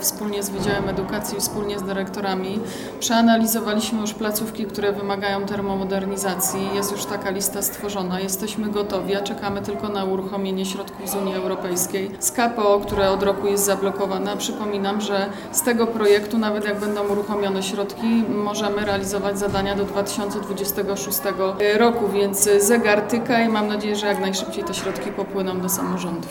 Wiceprezydent miasta Łodzi Małgorzata Moskwa-Wodnicka wspomina, że mają również listę placówek, które wymagają termomodernizacji.